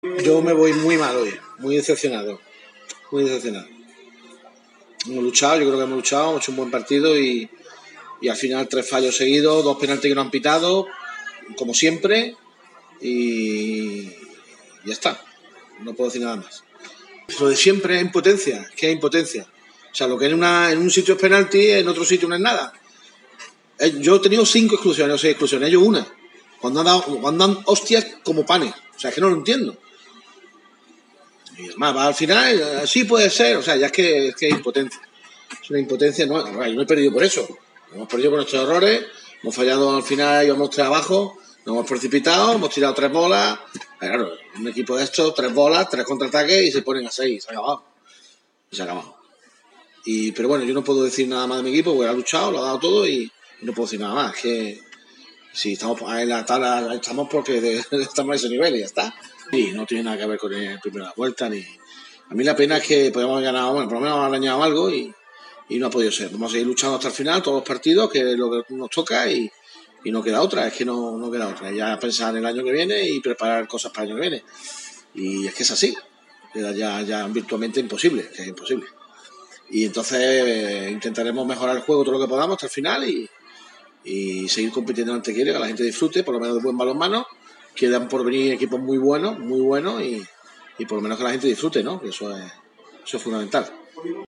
comparecía en rueda de prensa bastante indignado